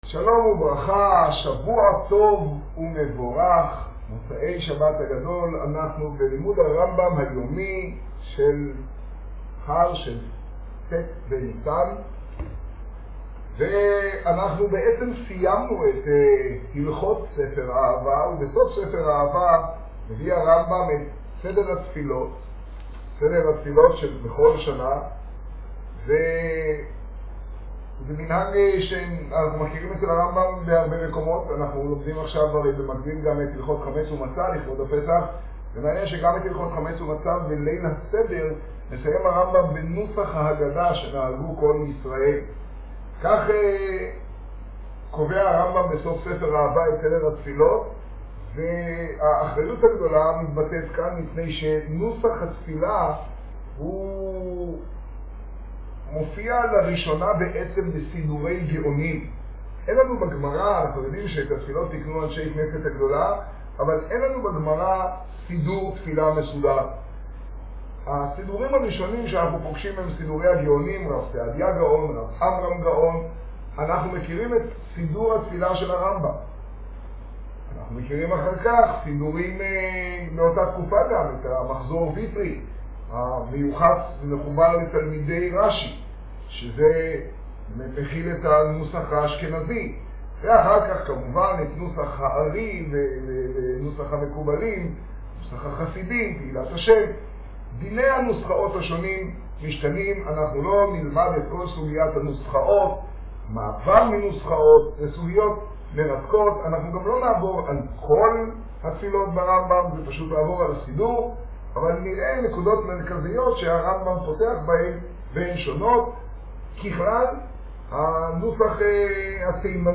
השיעור במגדל, ט ניסן תשעה.